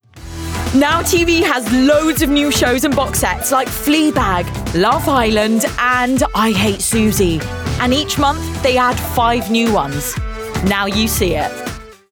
RP ('Received Pronunciation')
Commercial, Bright, Upbeat